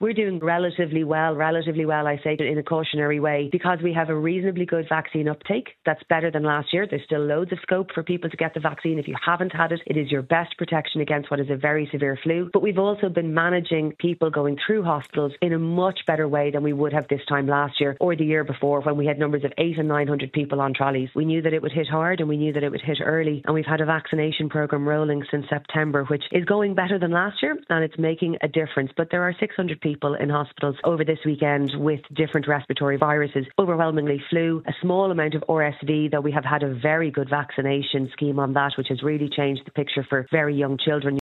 Health Minister Jennifer Carroll MacNeill, is advising people to get the flu vaccine………………